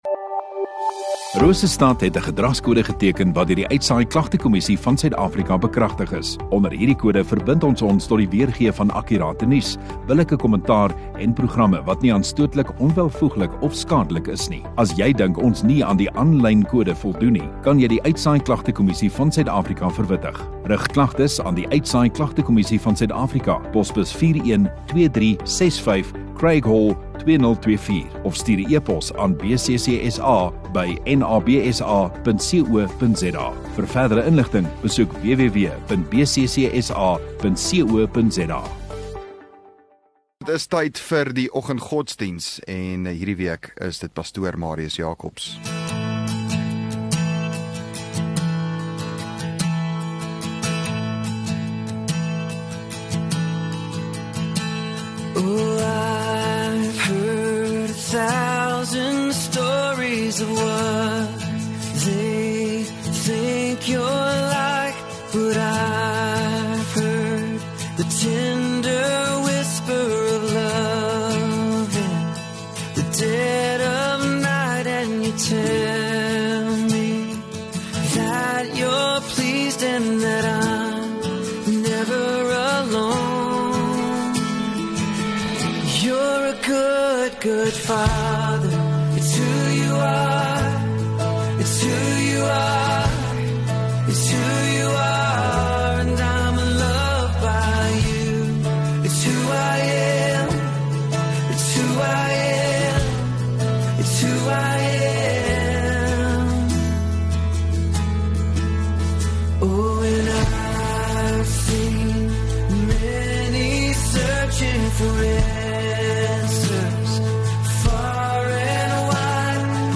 25 Feb Dinsdag Oggenddiens